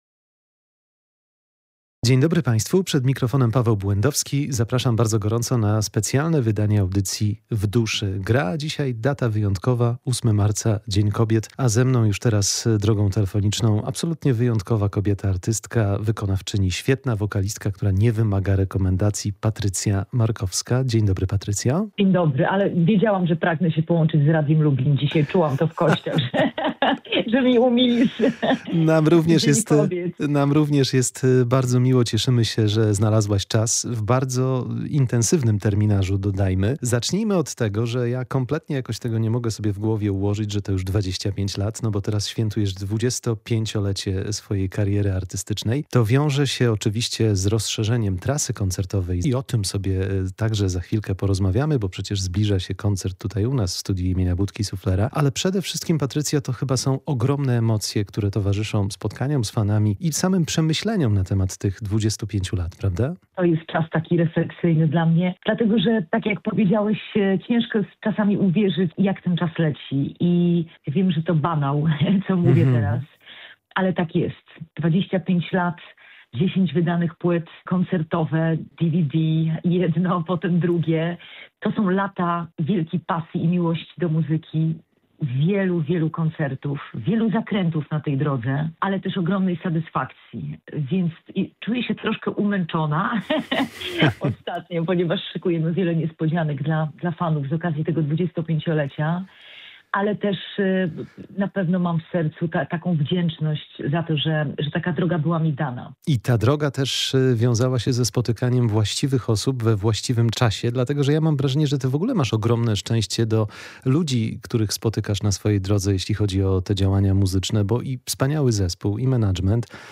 Patrycja Markowska w Dniu Kobiet przyjęła zaproszenie do audycji „W duszy gra”. W rozmowie